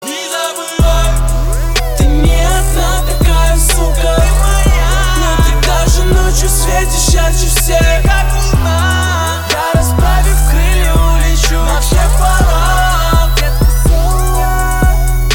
• Качество: 320, Stereo
лирика
русский рэп
Rap